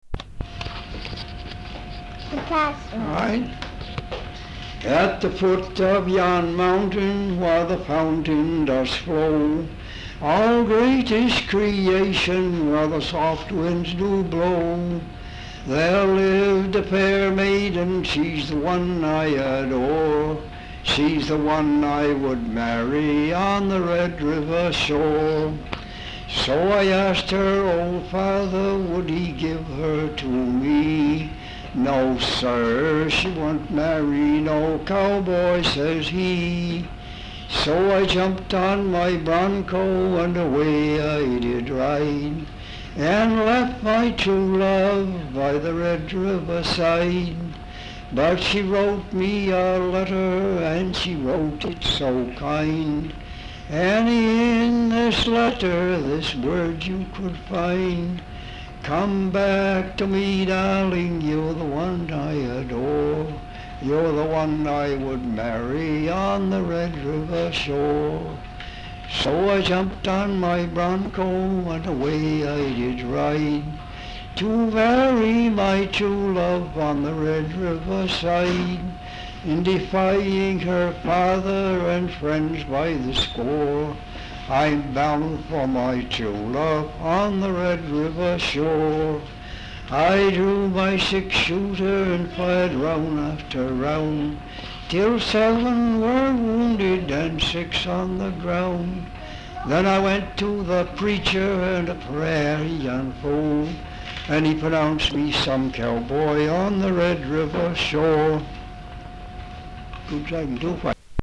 Folk songs, English--Vermont
sound tape reel (analog)
Location Dover, Vermont